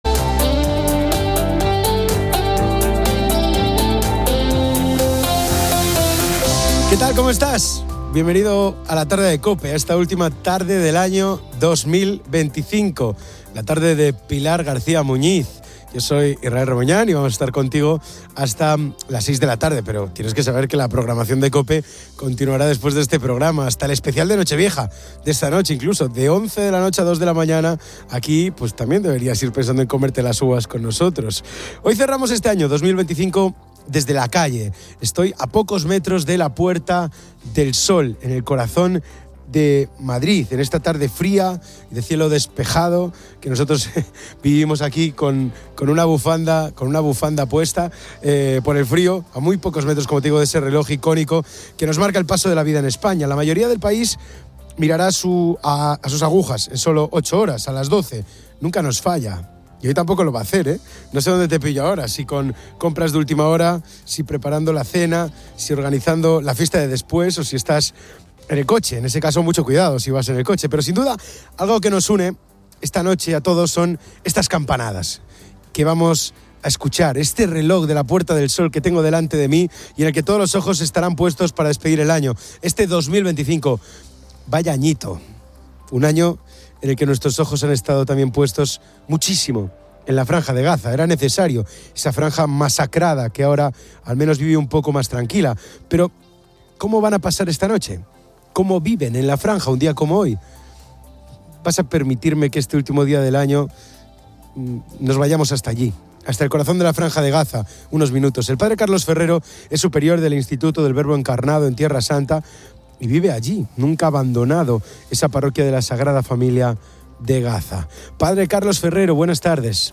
La emisión de fin de año de 2025 desde la Puerta del Sol en Madrid aborda la situación en Gaza.